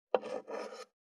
571肉切りナイフ,まな板の上,
効果音厨房/台所/レストラン/kitchen食器食材